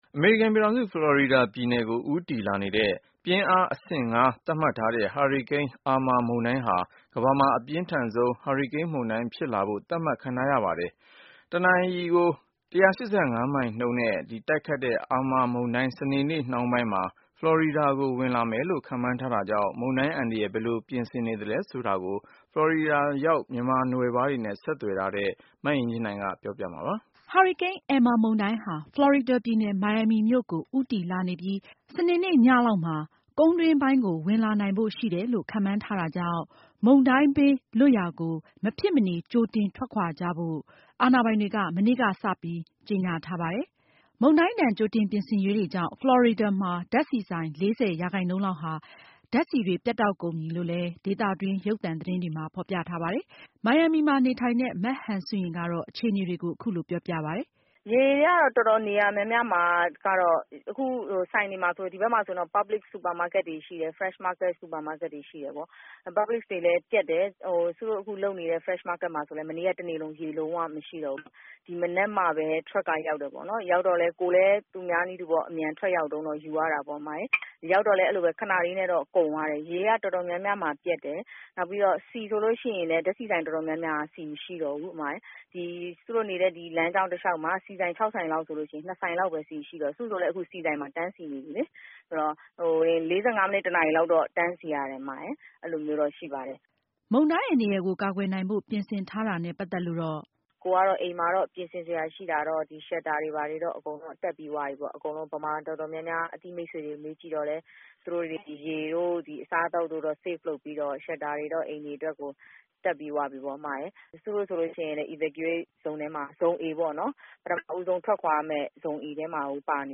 ဖလော်ရီဒါရောက် မြန်မာပြည်ဖွားတချို့နဲ့ ဆက်သွယ်မေးမြန်းထားတဲ့